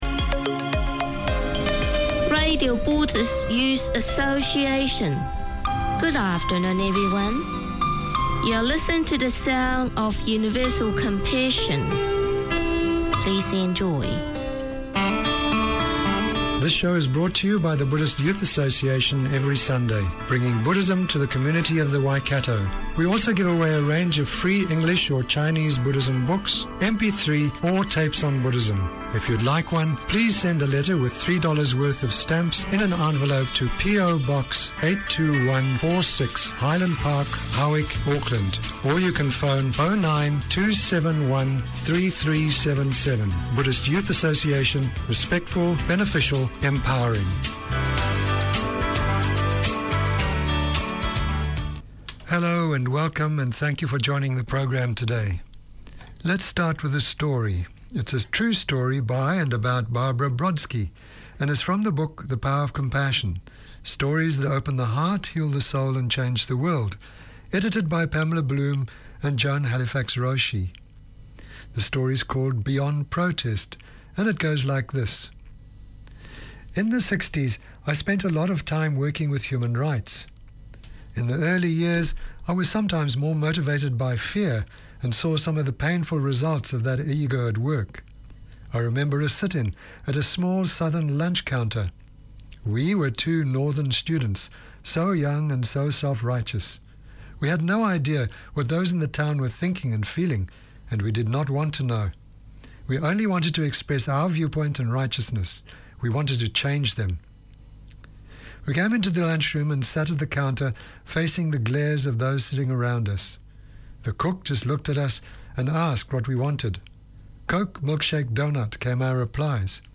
The radio show aims to open discussion on the progress of former refugees in Aotearoa/NZ through interviews, debate, news and talkback. Each week Resett Radio welcomes guests, listens to their stories and music and explores current affairs as they relate to resettlement communities today.